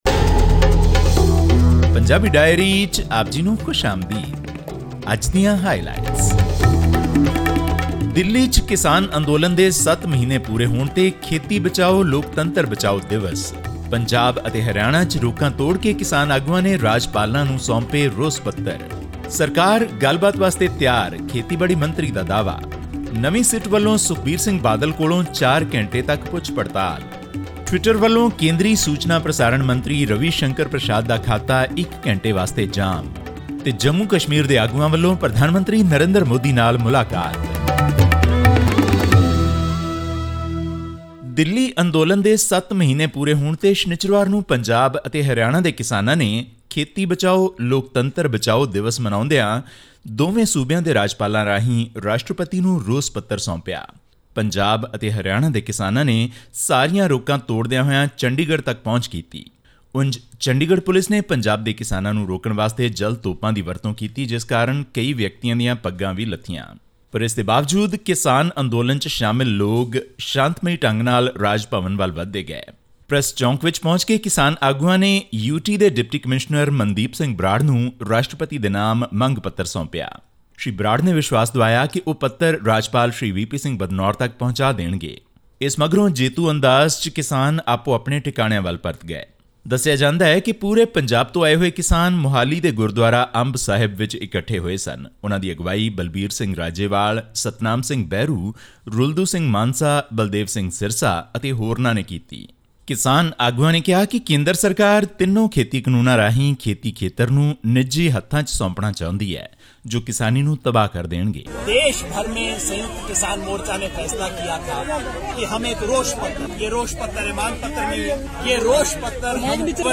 The Samyukt Kisan Morcha, a coalition of hundreds of farmer unions, observed 'Save Agriculture and Save Democracy Day' on 26 June to mark the 46th year of Emergency and seven months of their ongoing agitation against the government's three farm laws. This and more in our news bulletin from Punjab.